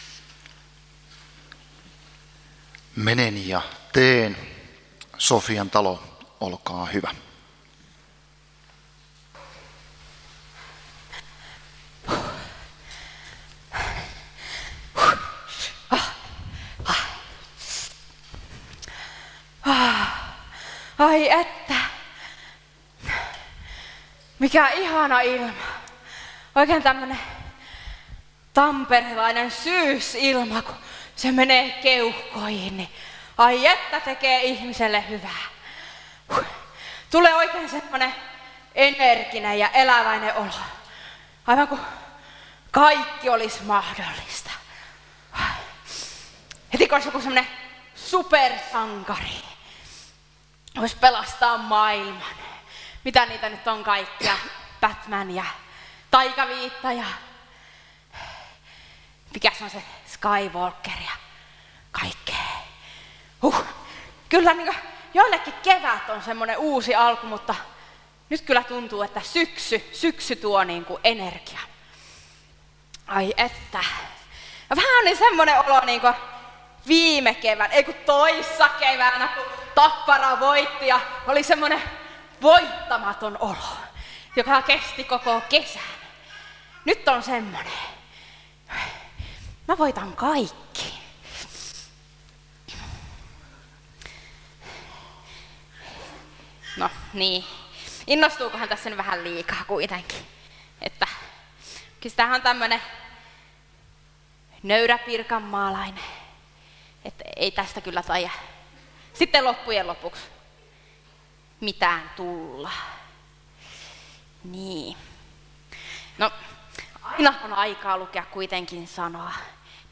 Sofian talon draama Tampereen evankeliumijuhlilla lauantaina.